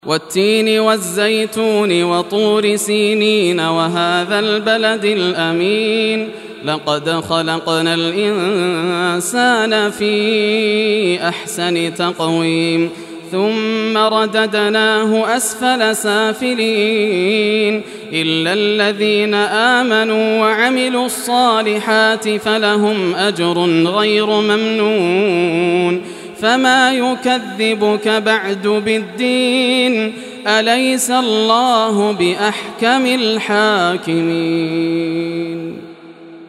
Surah At-Tin Recitation by Yasser al Dosari
Surah At-Tin, listen or play online mp3 tilawat / recitation in Arabic in the beautiful voice of Sheikh Yasser al Dosari.